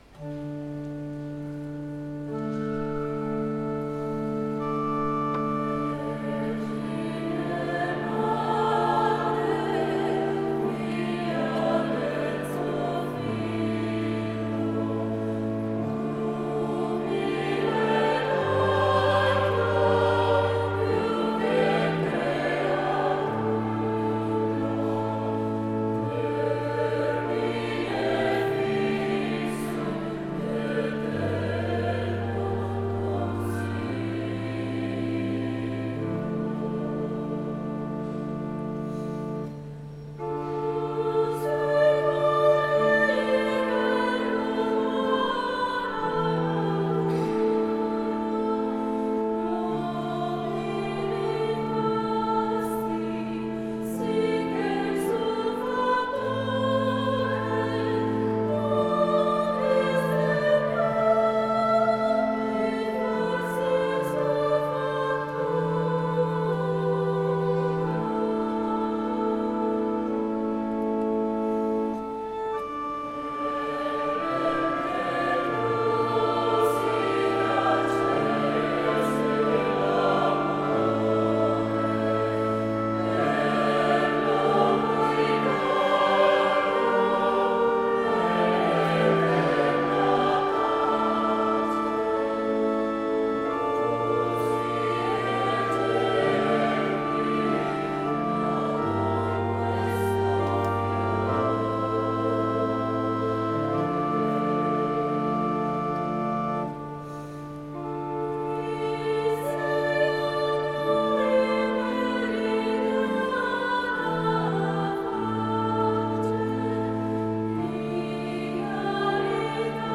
Gallery >> Audio >> Audio2017 >> Rassegna Corali Diocesane >> 05-RassCorali 26Nov2017 IstMissCarini